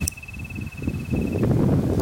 Darwin´s Nothura (Nothura darwinii)
Life Stage: Adult
Country: Argentina
Condition: Wild
Certainty: Recorded vocal